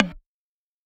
MB Perc (10).wav